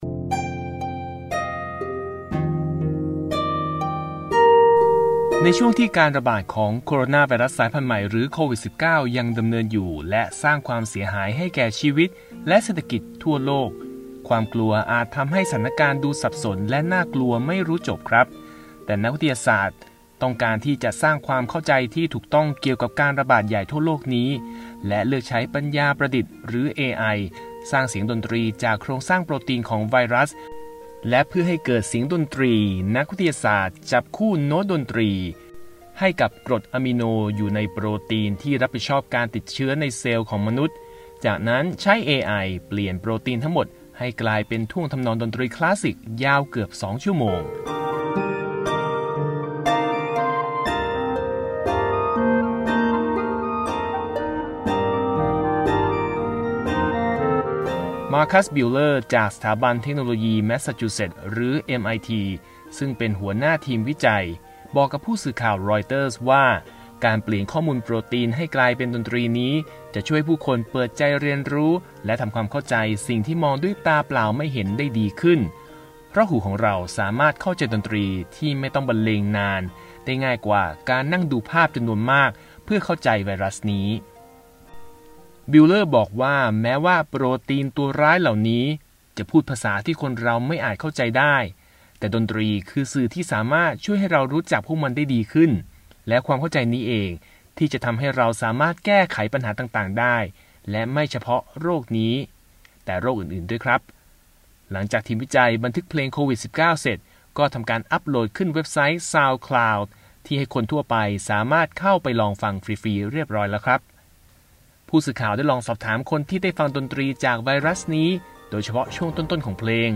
และหลังจากไวรัสเข้าไปในเซลล์สำเร็จ ก็จะเกิดการแบ่งตัว ซึ่งทำให้ดนตรีนั้นเริ่มดังขึ้น ดำเนินท่วงทำนองที่เร็วขึ้น และเริ่มหนักหน่วงขึ้น